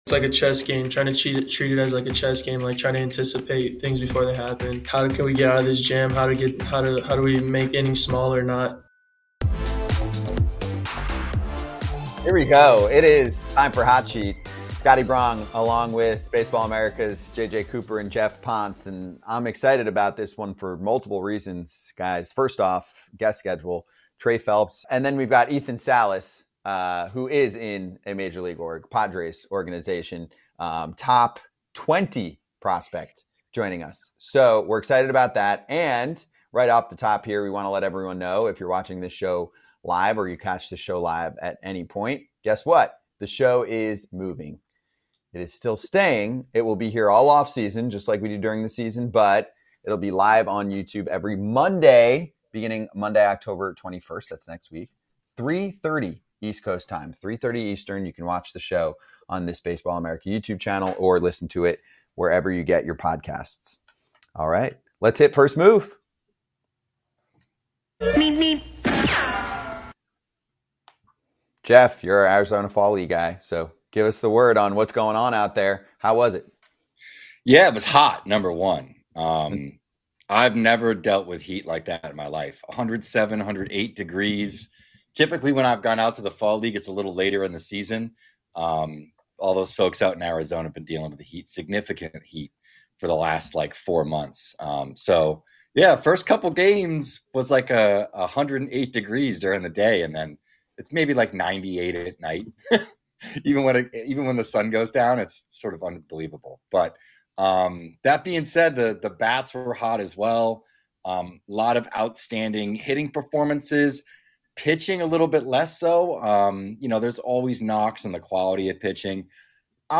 We have a pair of really fun conversations today.